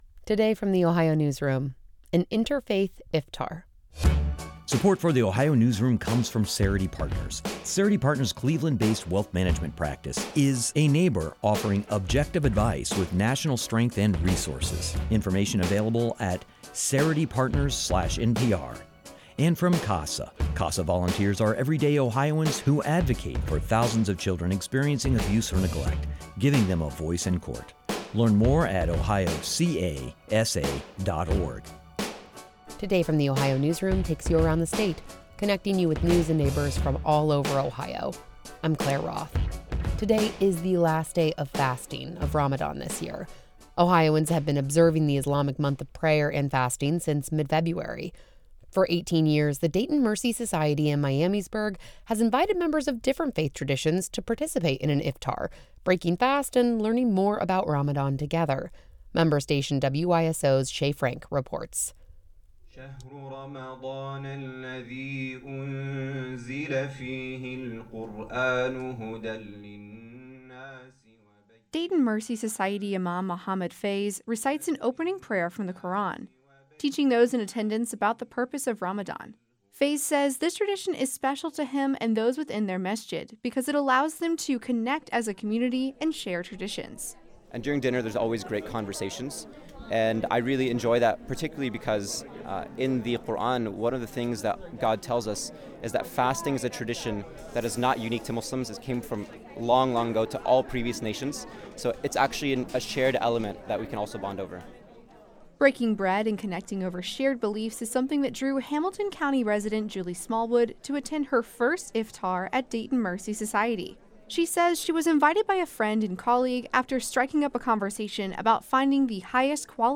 This year's Interfaith Iftar took place on Feb. 25, hosted by Dayton Mercy Society in Miamisburg.
The evening began with the recitation of an opening prayer from the Quran, teaching those in attendance about the purpose of Ramadan.
The athan, or call to prayer, began as the sun fully set, inviting members of Dayton Mercy Society to gather in the upstairs mosque.